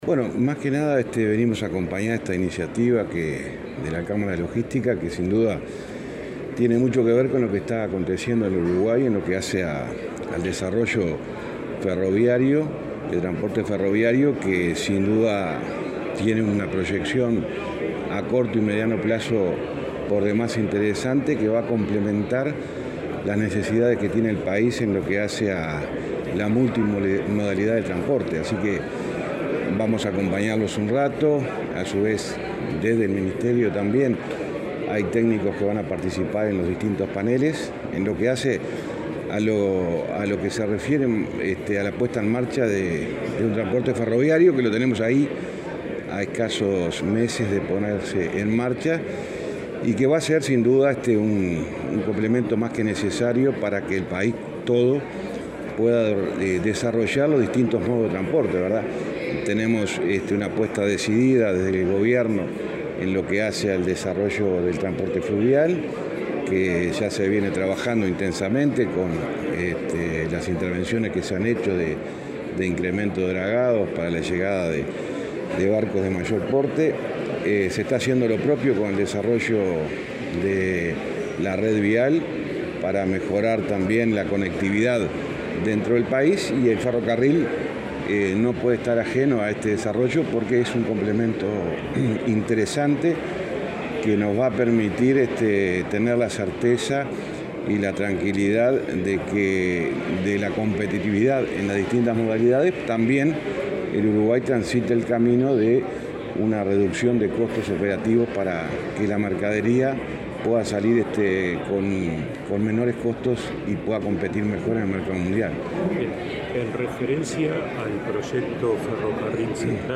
Declaraciones del ministro de Transporte, José Luis Falero
El ministro de Transporte, José Luis Falero, dialogó con la prensa luego de participar en un encuentro sobre el ferrocarril como apoyo a la cadena